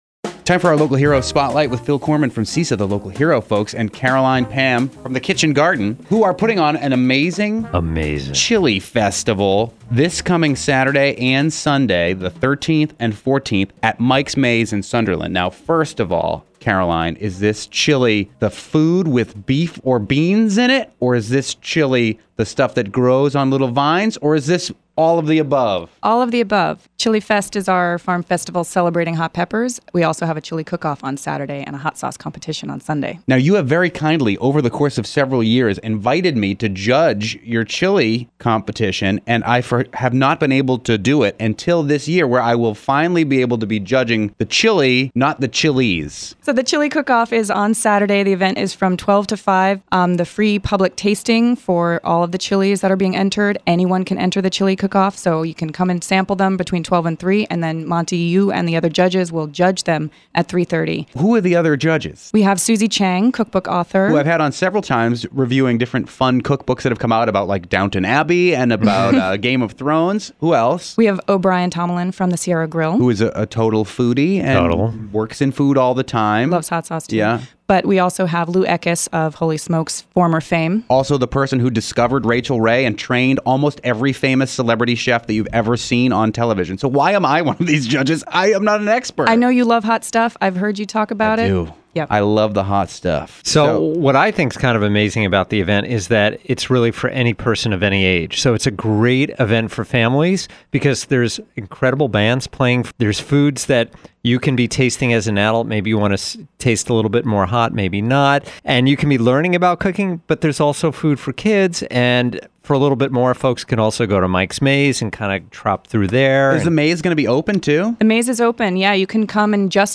Interview: The Kitchen Garden’s Chilifest